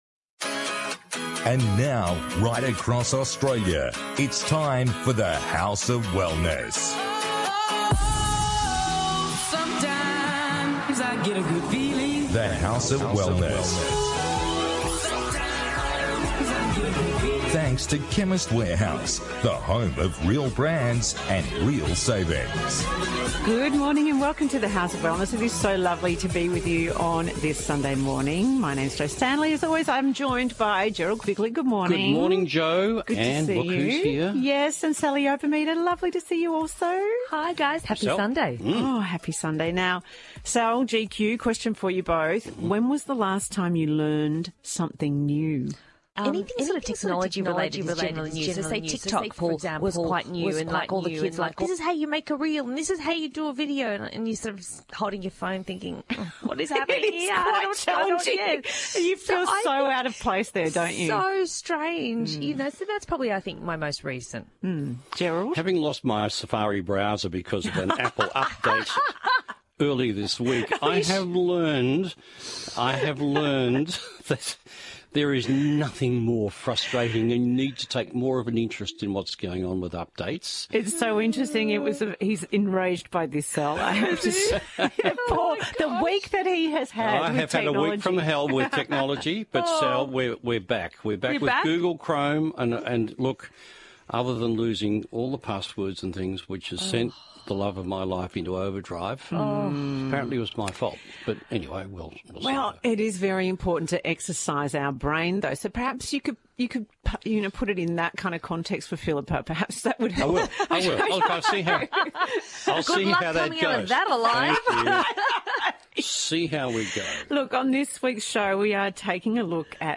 On this week’s The House of Wellness radio show the team discusses: